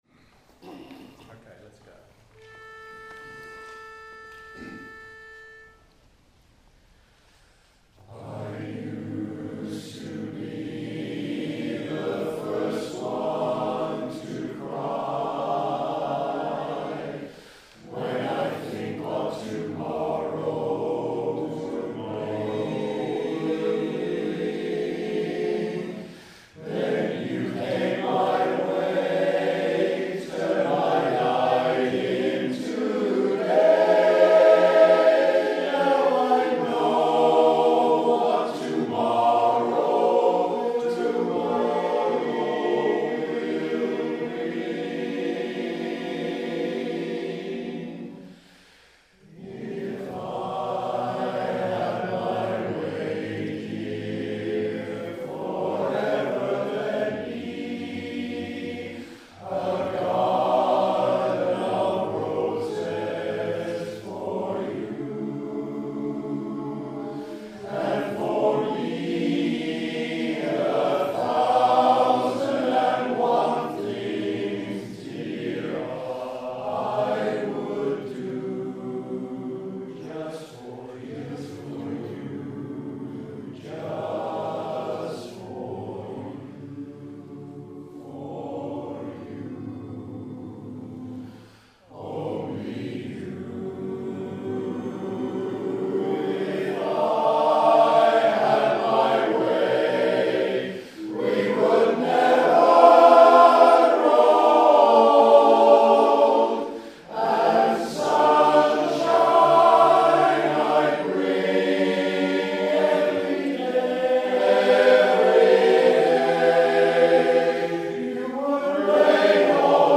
British Association Of Barbershop Singers (BABS) Quartet Exploration Day – South West Region
The result of just a few hours together singing ‘If I Had My Way’ for the very first time.
Quartet-Day-Final-chorus-recording.mp3